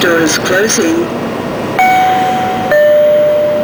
Almost Every 7k Announcement
The ones marked "CAF" are the ones that were initially rolled out on the CAF 5000-Series railcars, and then they were used on the Breda 2000 and 3000-Series railcars following their rehabilitation in the mid 2000s.